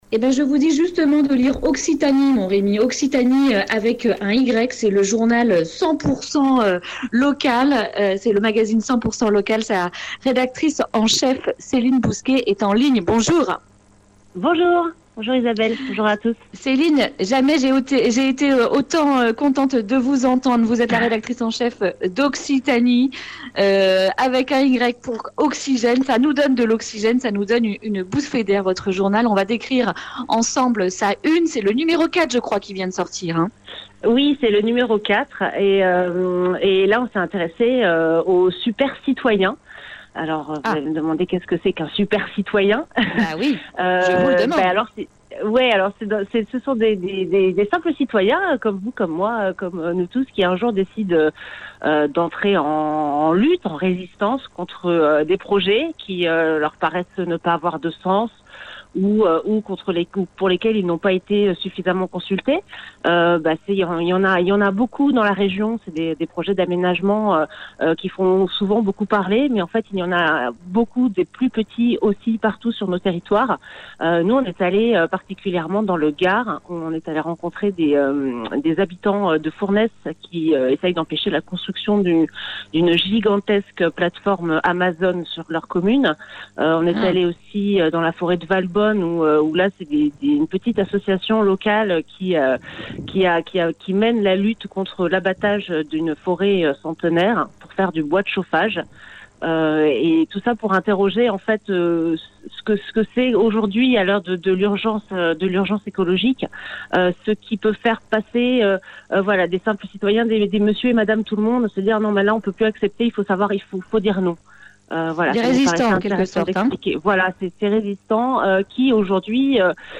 jeudi 19 mars 2020 Le grand entretien Durée 11 min
Une émission présentée par